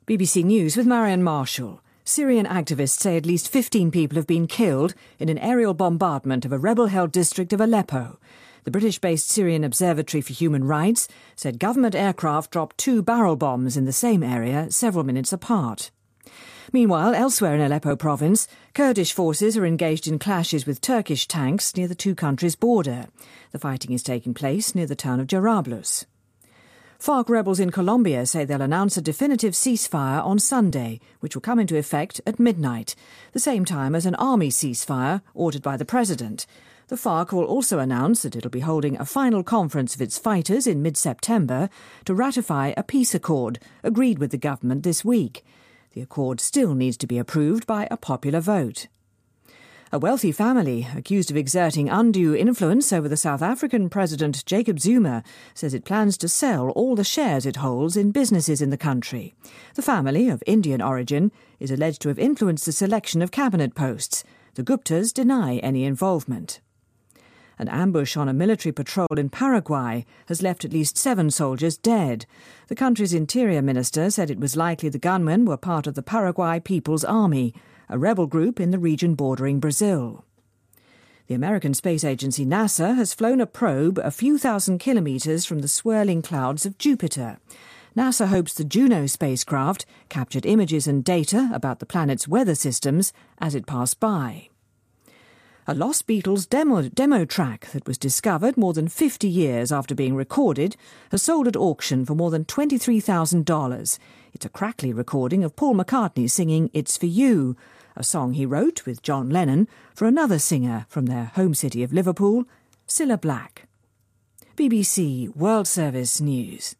BBC news,古普塔家族年底前裸退南非